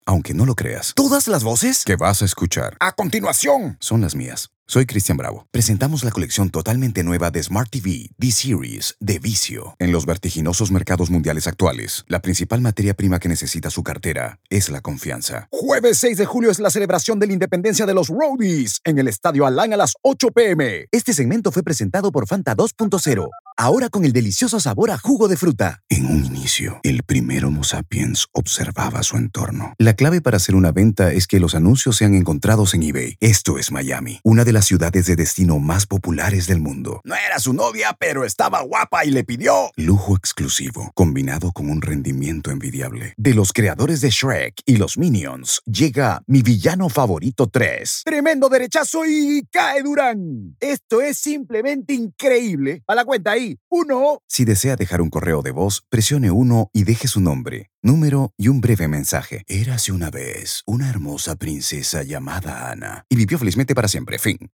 Hola! My voice has a neutral accent so the versatility in its use for all Spanish speaking is assured. Corporate, Romantic, Smooth, Warm and Deep Voice.
Sprechprobe: Werbung (Muttersprache):